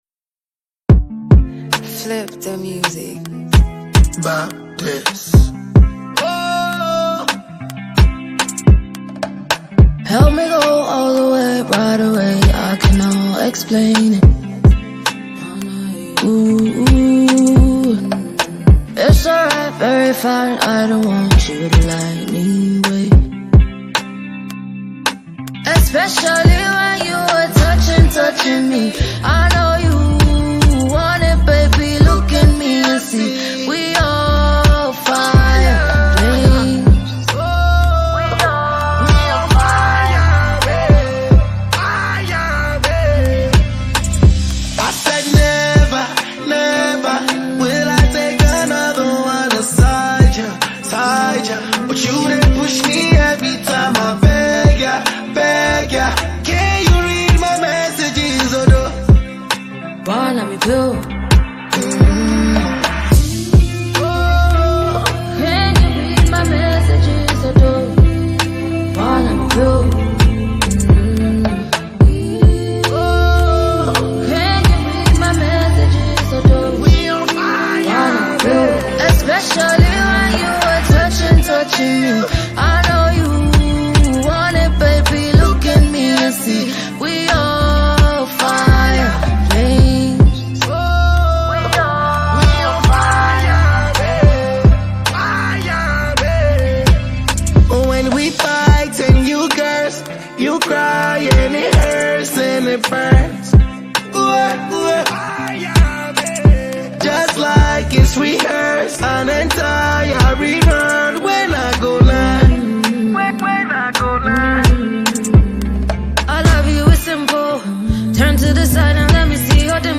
Highly gifted Ghanaian afrobeat singer-songwriter